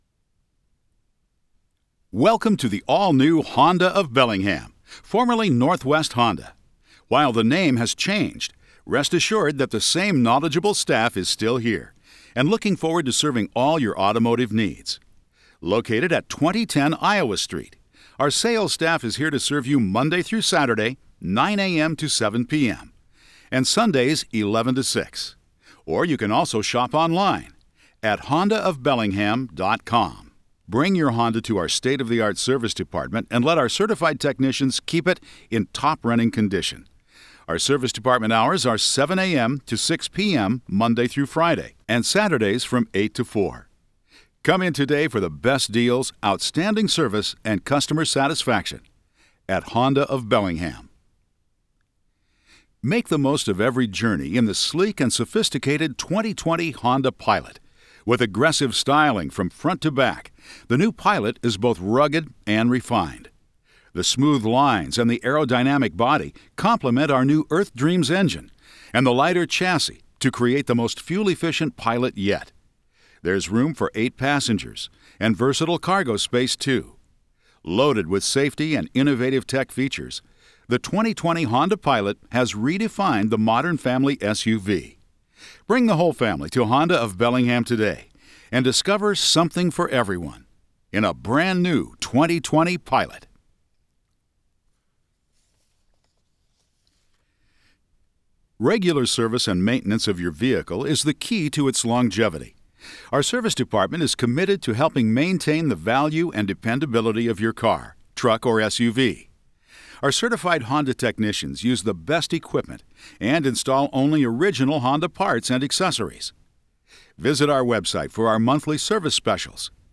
Industrial Voice-Over
I also narrate public-facing content such as automated phone systems and safety and instructional material.